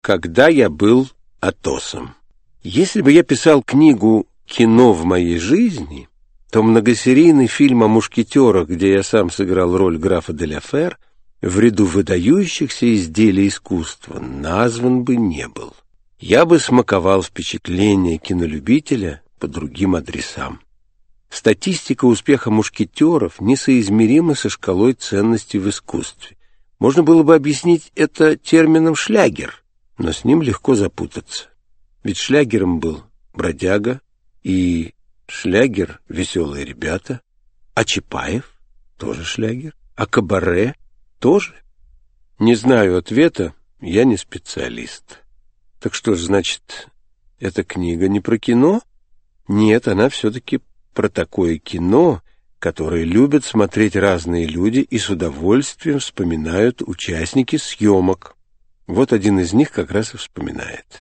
Юрий Любимов Автор Вениамин Смехов Читает аудиокнигу Вениамин Смехов.